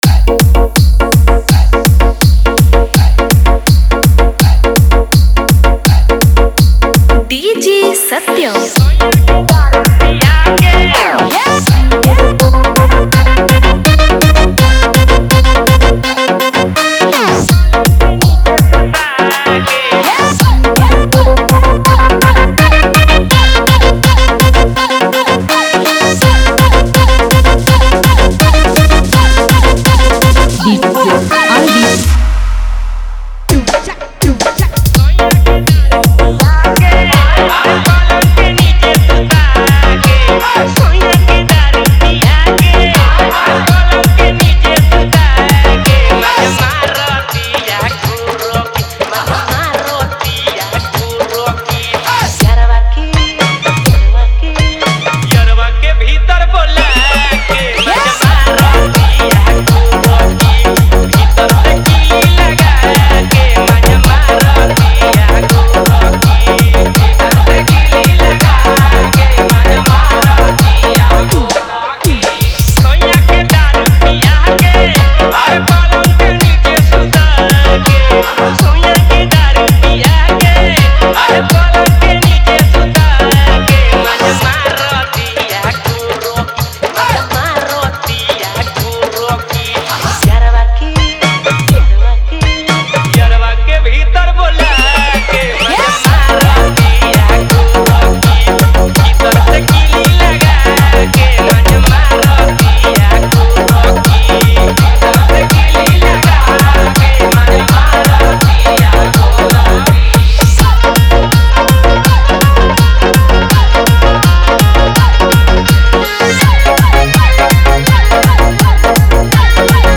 Bhojpuri DJ Remix Songs